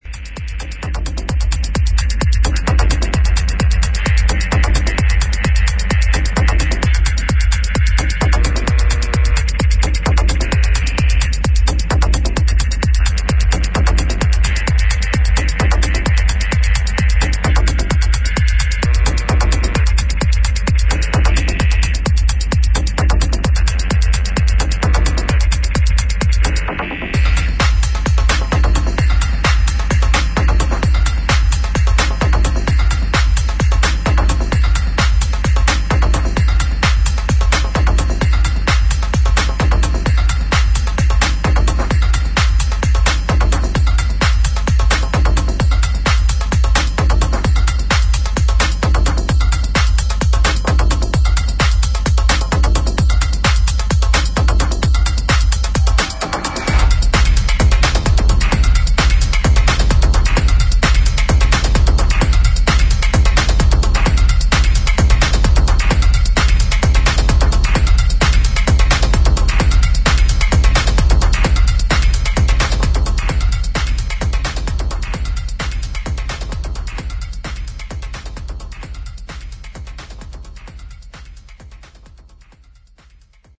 [ TECHNO / INDUSTRIAL ]